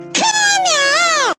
valorant jett ke miao Meme Sound Effect
Category: Games Soundboard